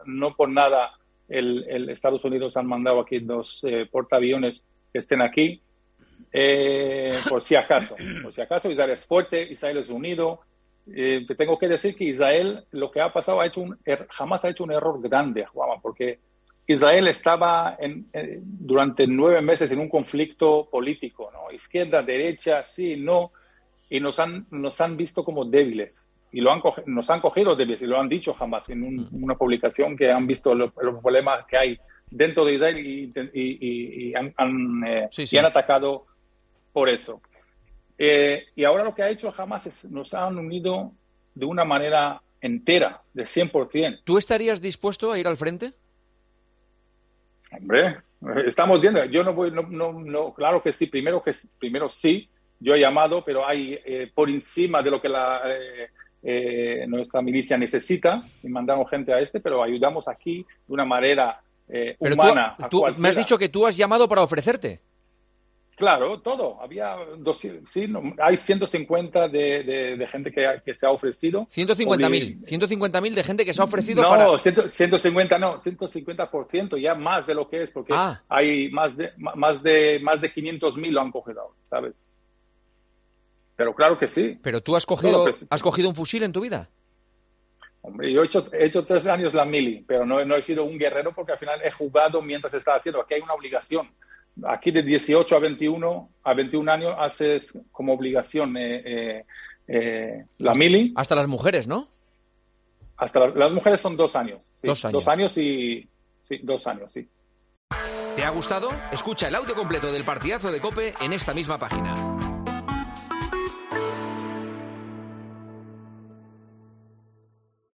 Este miércoles ha pasado por los micrófonos de El Partidazo de COPE David 'Dudu' Aouate, exportero del Racing de Santander, que en los últimos días tuvo un cruce de palabras con Karim Benzema en redes sociales por la guerra entre Israel y Hamás.